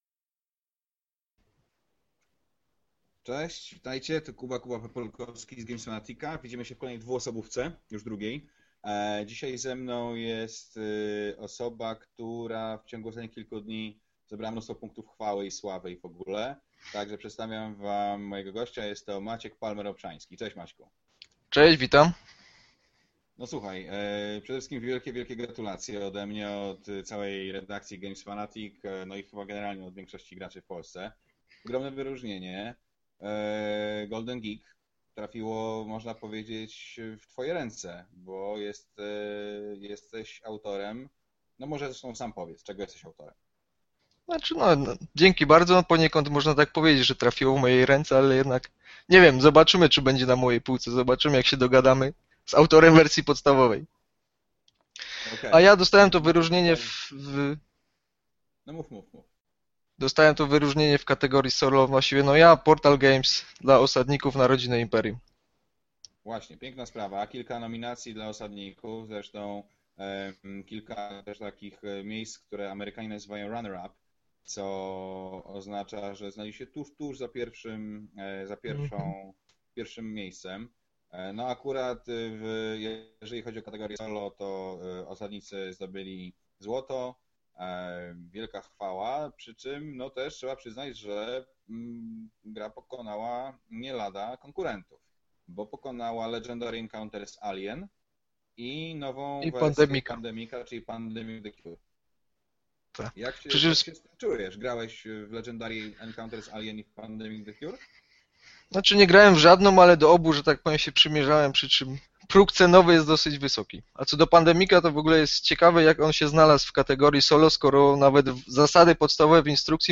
krótką rozmowę w naszej Dwuosobówce